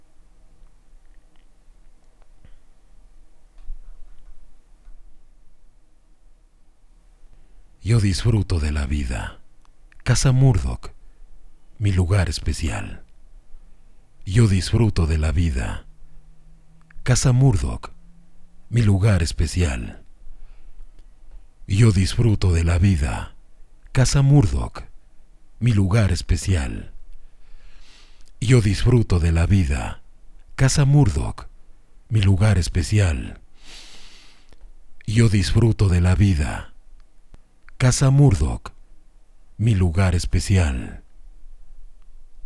Barítono bajo, con acento neutro, que puede alcanzar figuras detalladas en el romance o en la narración.
Sprechprobe: Werbung (Muttersprache):